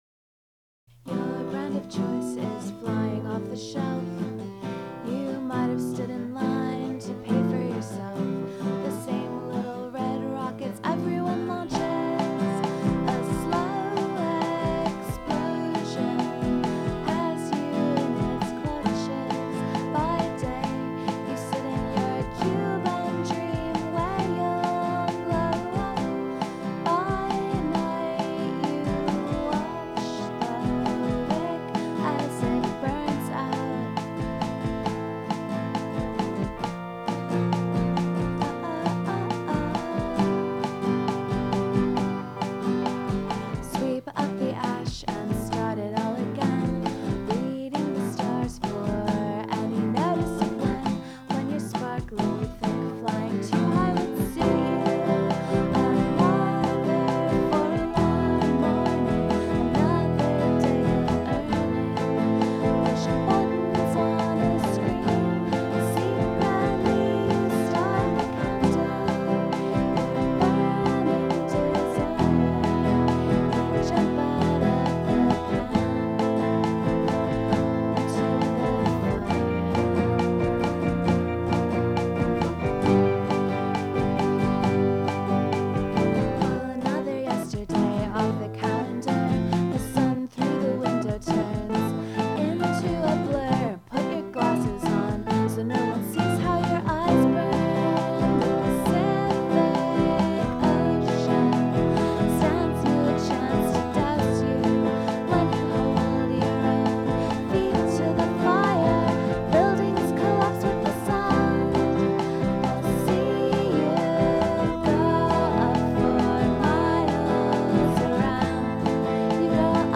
new version with lousy singing/playing